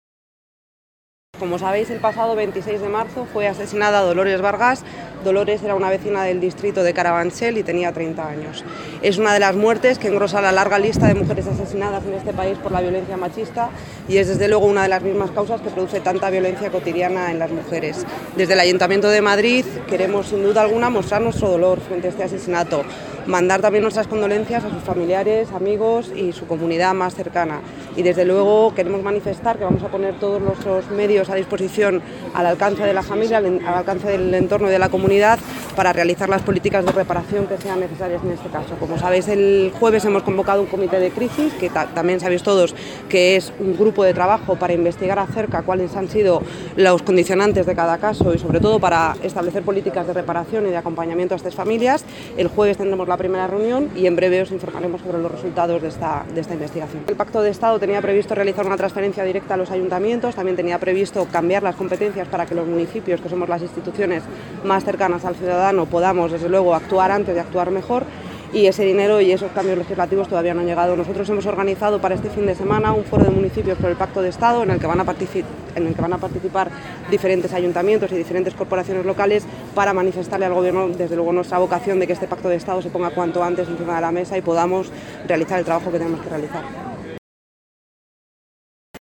Nueva ventana:Declaraciones de Celia Mayer tras el minuto de silencio